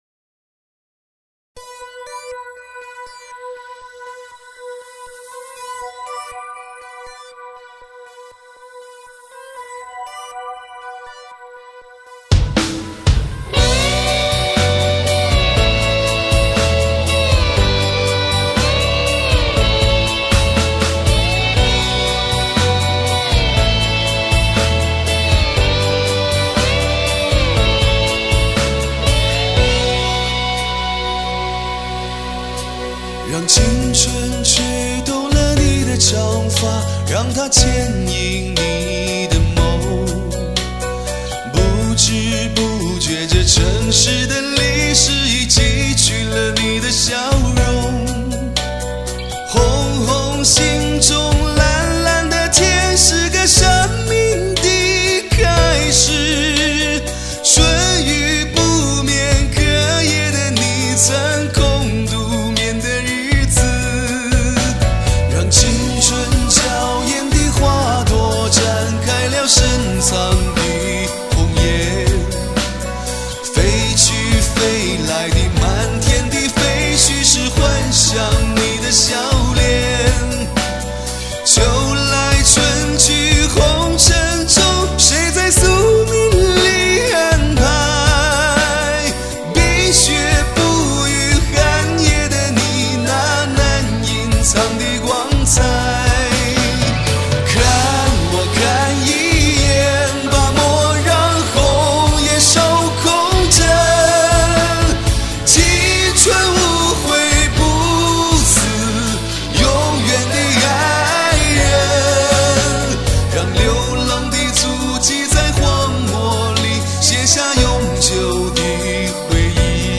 翻唱一首摇滚版的
有些声嘶力竭了。